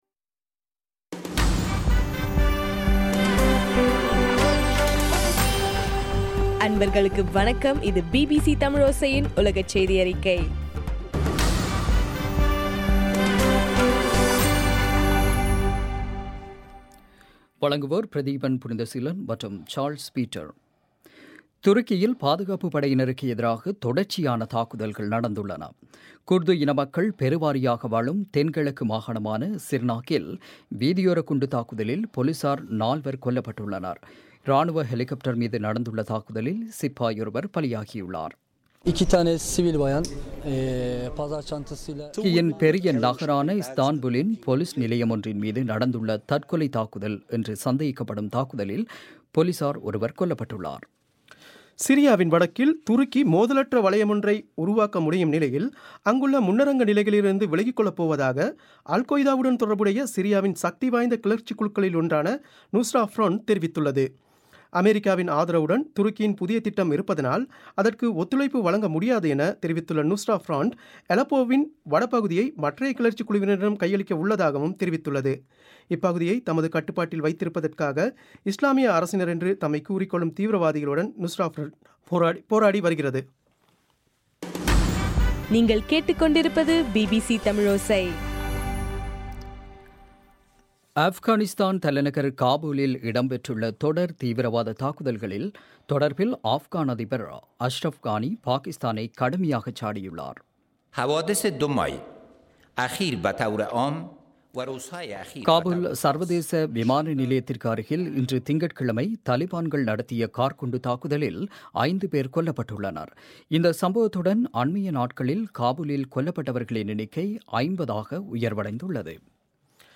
ஆகஸ்ட் 10, 2015 பிபிசி தமிழோசையின் உலகச் செய்திகள்